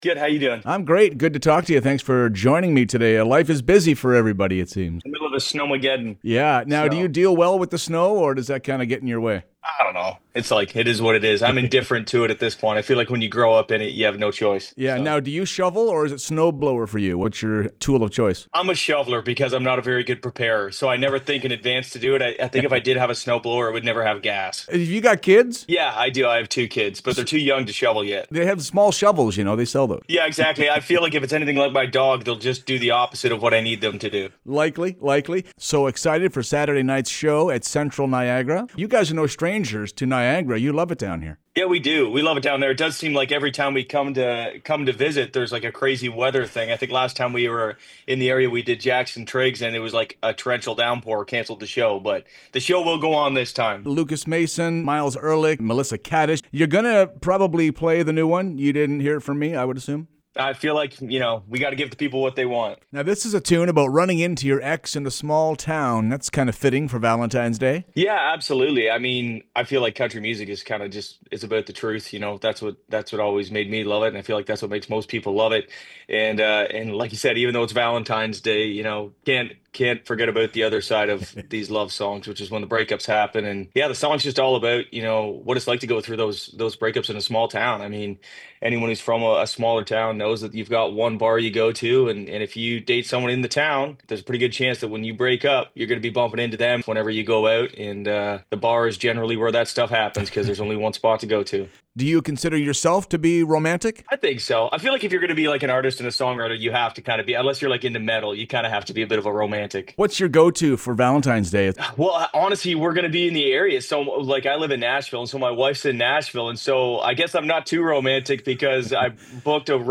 Tune in every Friday morning for weekly interviews, performances, everything LIVE!